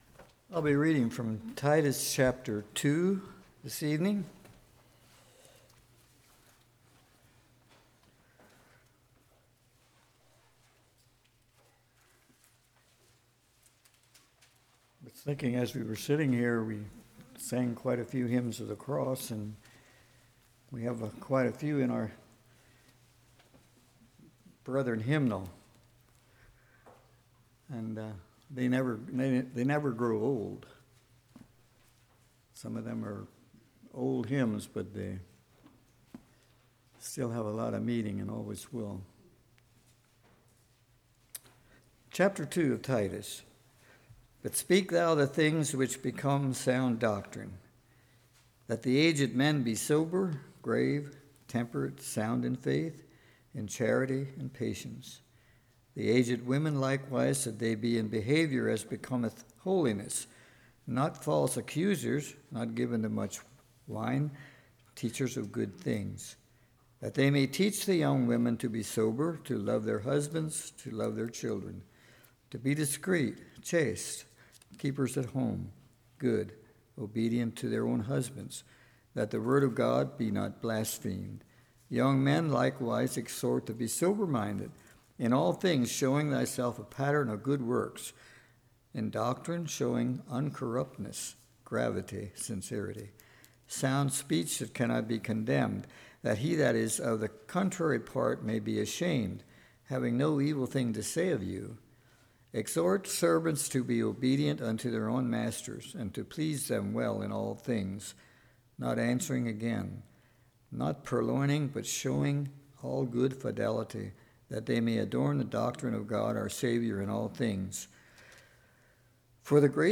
Titus 2:1-15 Service Type: Evening What Was The Doctrine?